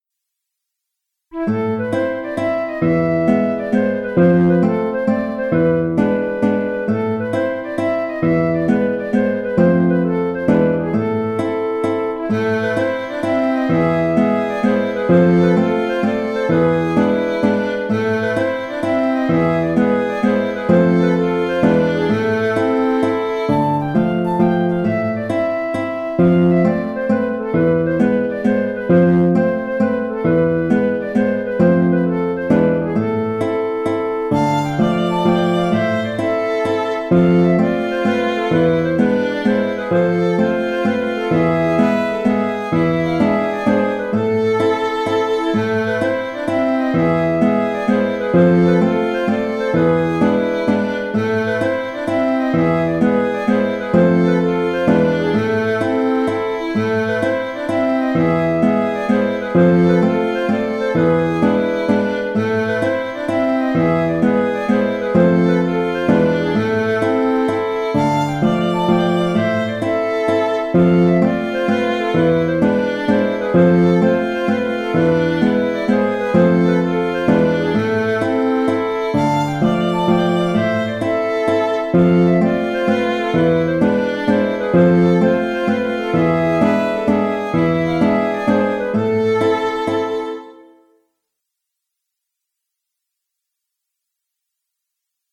Sur une même mélodie, aux accents de java, je propose deux interprétations différentes. L’une sur le rythme ternaire de la mazurka, l’autre sur un rythme de valse, plus rond et plus rapide.
Fricotine (La) - Mazurka